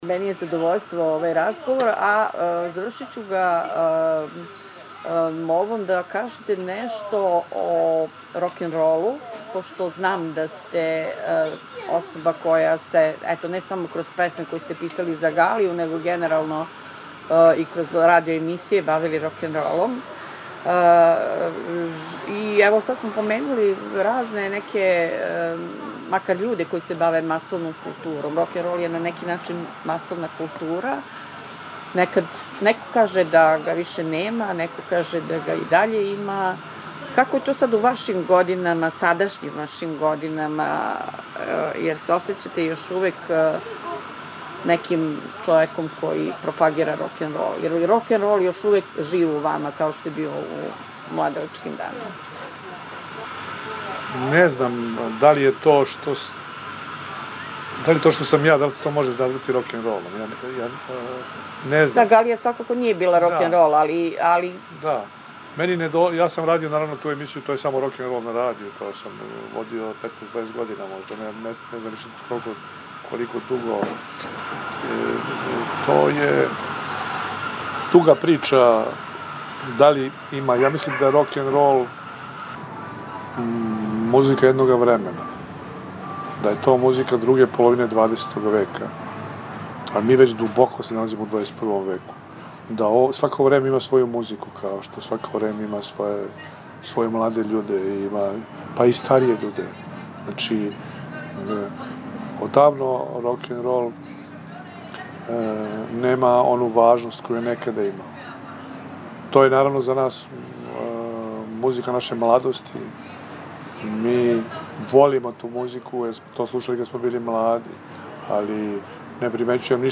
ГОСТОПРИМНИЦА – Интервју
Срели смо се након промоције и ево разговора који смо водили.